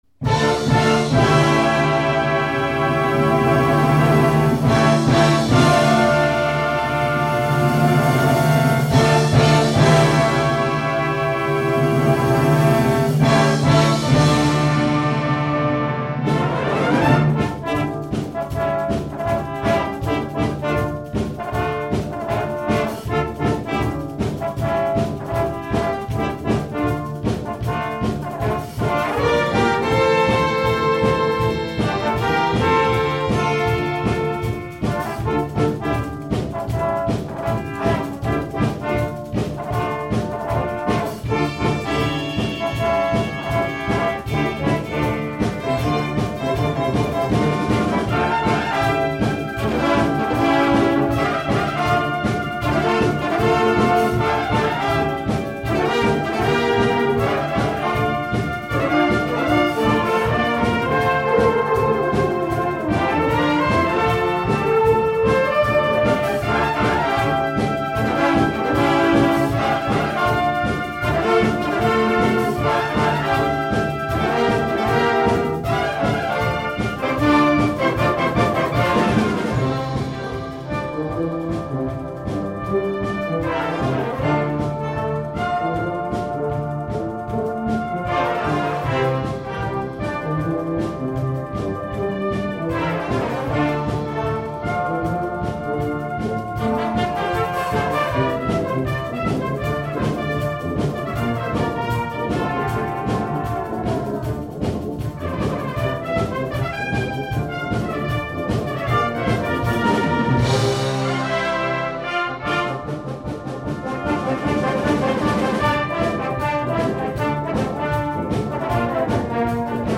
trombone soloist